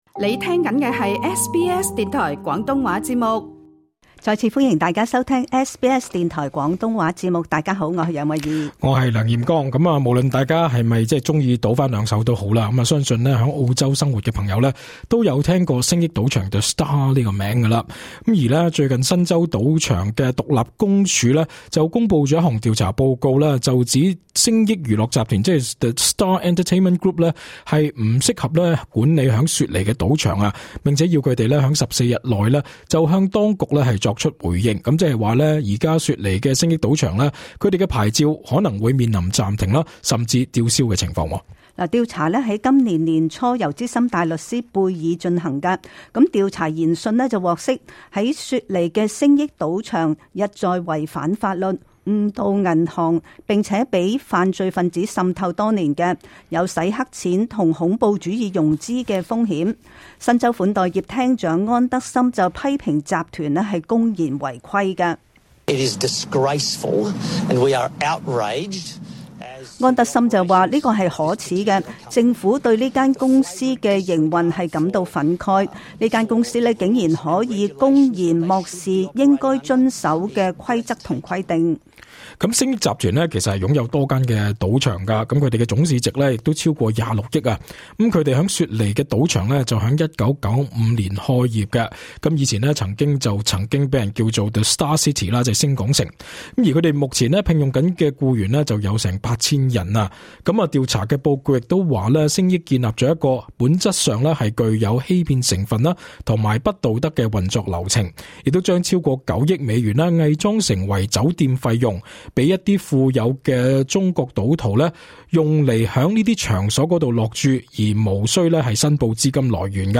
時事報道